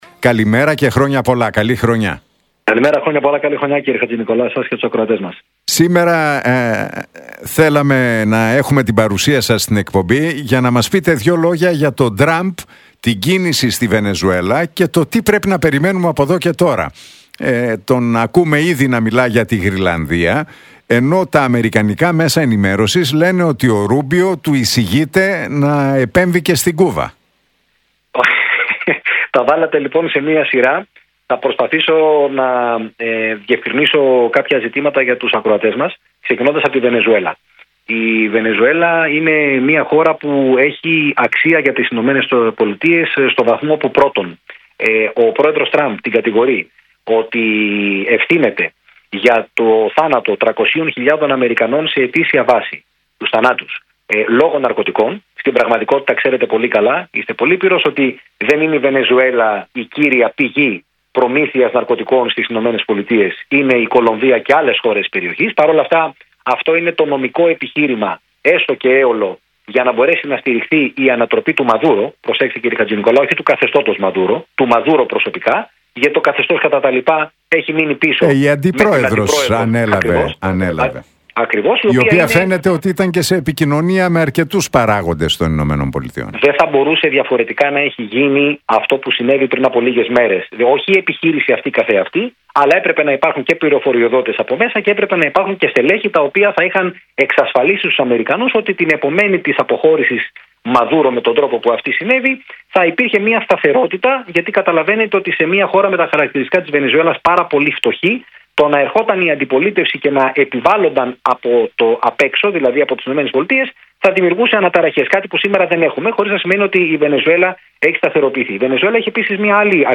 Τους λόγους για τους οποίους η Βενεζουέλα έχει αξία για τις ΗΠΑ εξήγησε ο διεθνολόγος, Κωσταντίνος Φίλης στον Realfm 97,8 και τον Νίκο Χατζηνικολάου, ενώ αναφέρθηκε στους σχεδιασμούς Τραμπ για τη Γροιλανδία και τη στάση που τηρεί η Ευρώπη.